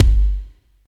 34 KICK 4.wav